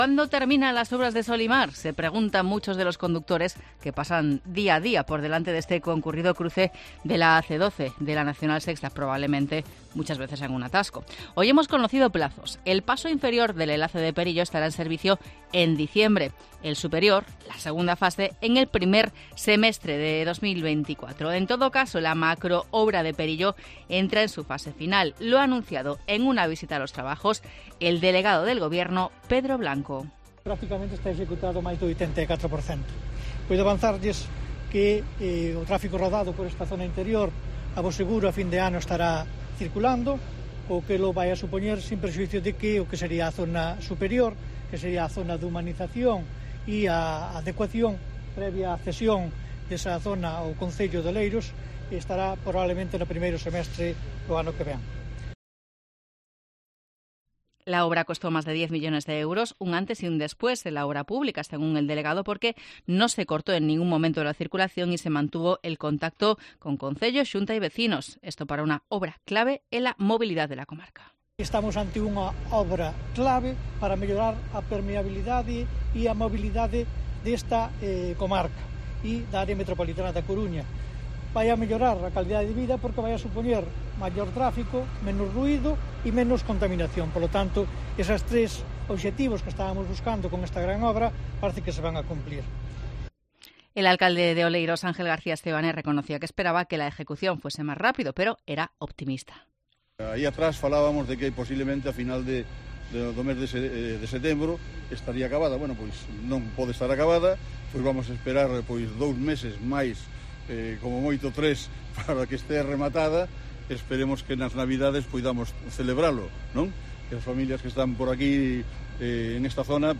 Crónica sobre la visita a las obras del cruce de Sol y Mar, en Oleiros